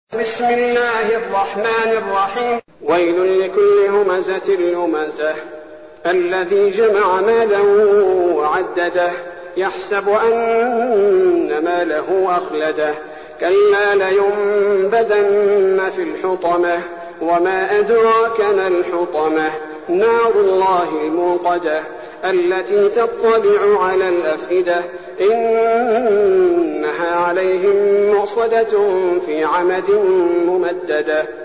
المكان: المسجد النبوي الشيخ: فضيلة الشيخ عبدالباري الثبيتي فضيلة الشيخ عبدالباري الثبيتي الهمزة